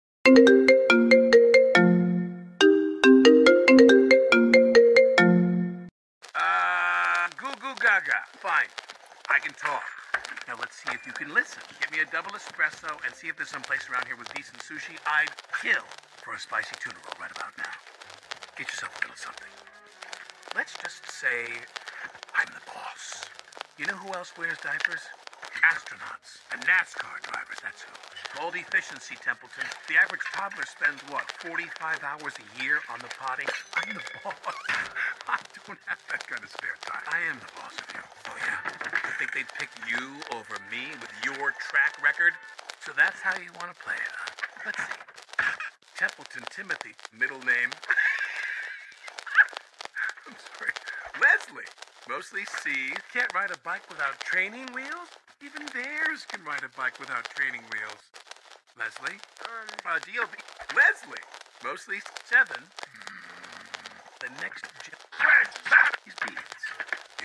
Perfect for fans of funny fake calls, Boss Baby moments, and prank call videos!